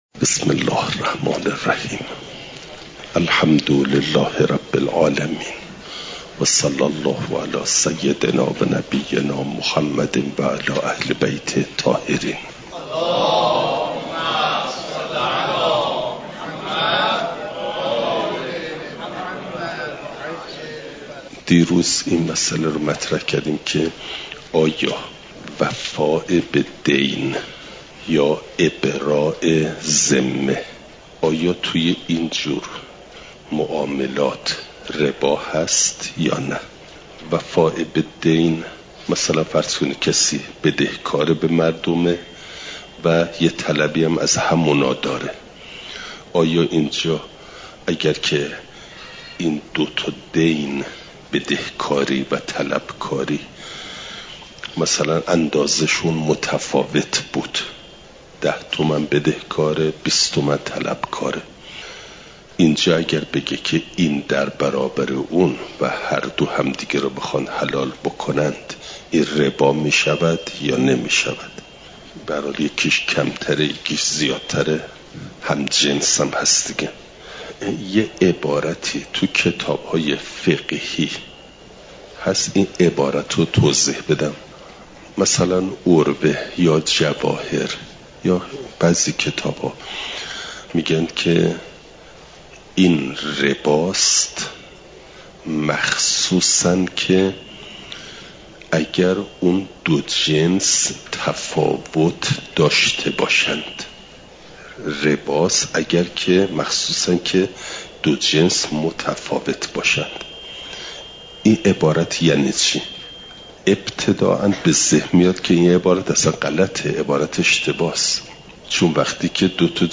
نظام اقتصادی اسلام؛ مبحث ربا (جلسه۱۶) – دروس استاد